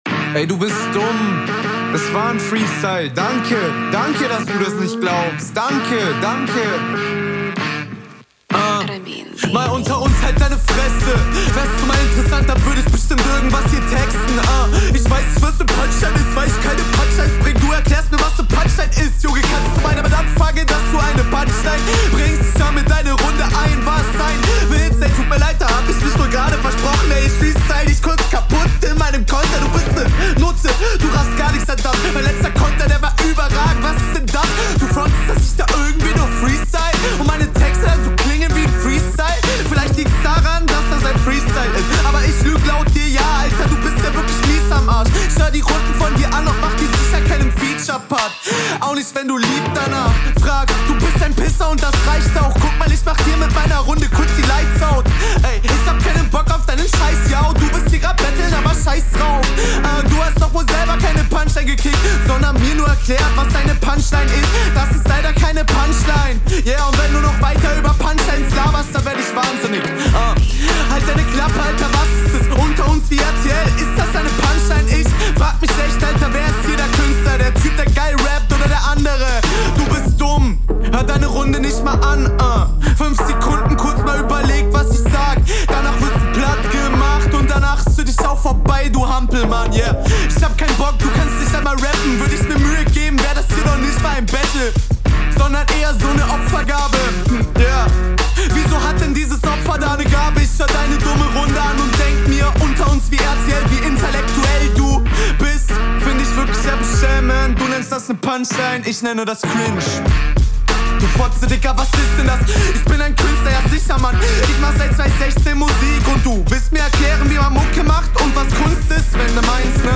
Flow wieder richtig chill und nice.